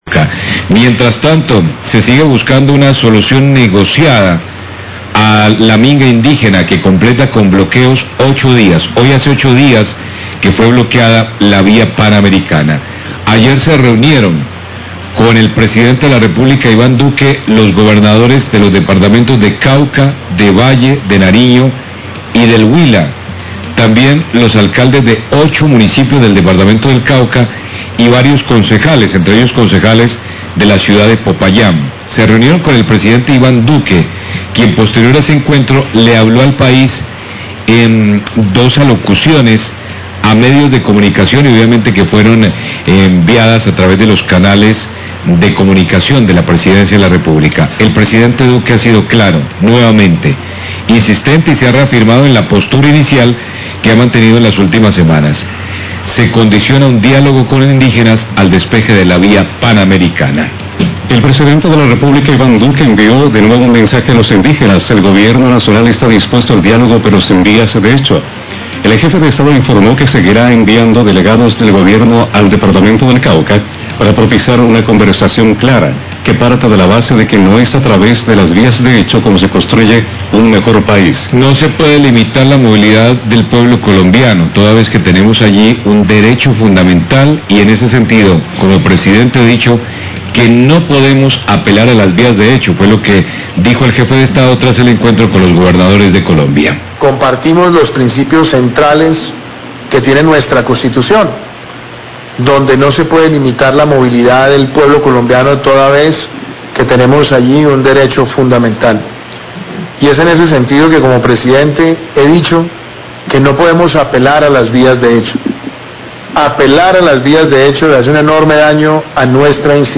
COMUNICADO PDTE DUQUE LUEGO DE REUNIÓN CON GOBERNADORES DE VALLE, CAUCA Y NARIÑO
Radio